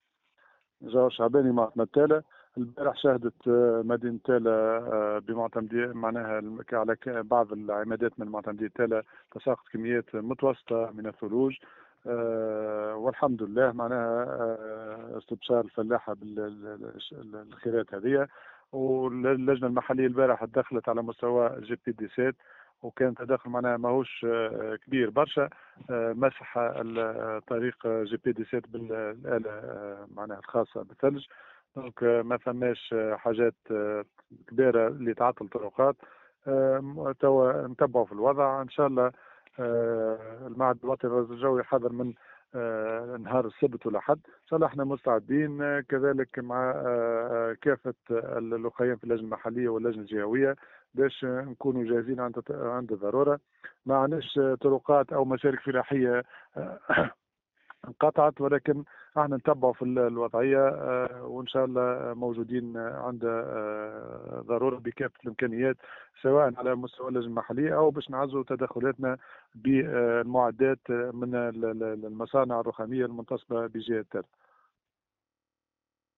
مزيد التفاصيل في التصريحات التالية لجوهر شعباني معتمد تالة:
جوهر-شعباني-معتمد-تالة-.mp3